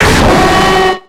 Cri de Démolosse dans Pokémon X et Y.